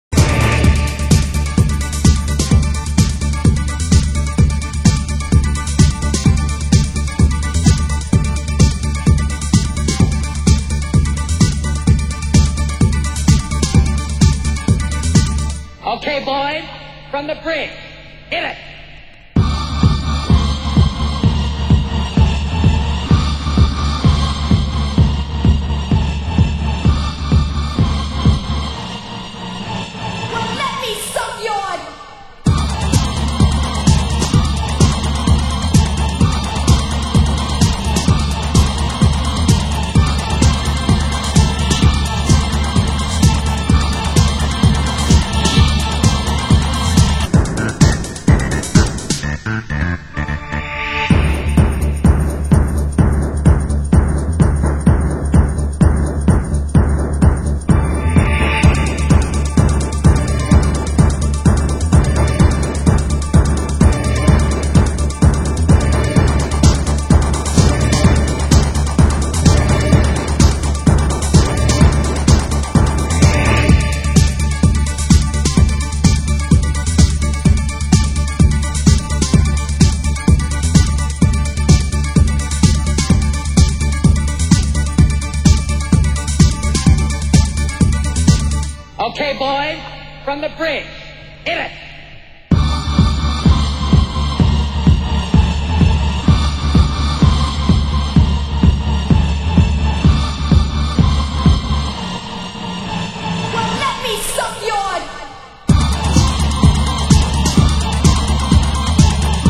Genre: Euro Rave (1990-92)